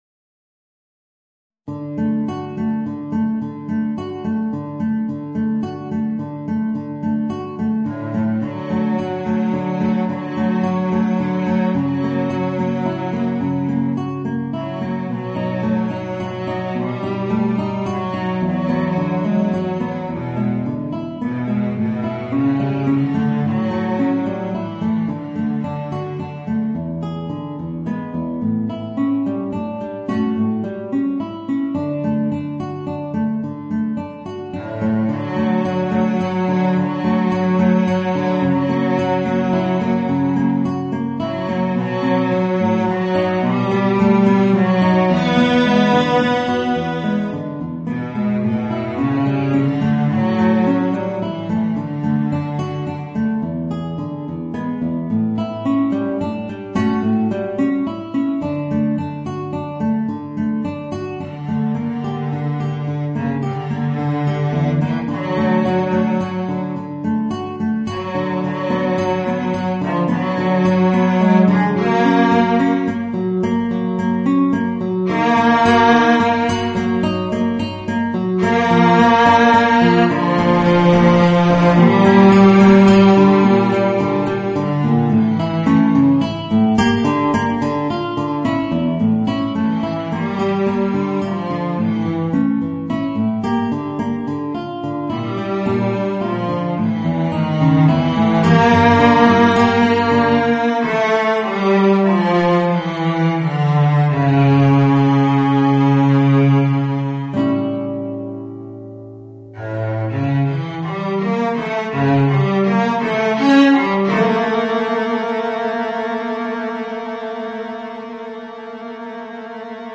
Voicing: Violoncello and Guitar